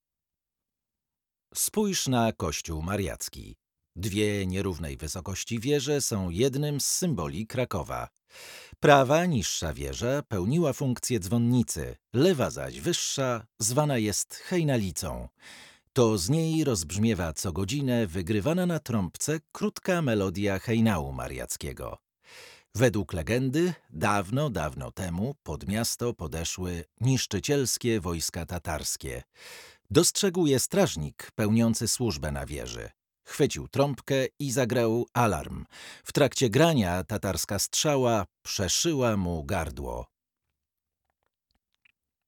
Duże możliwości barwowe i interpretacyjne.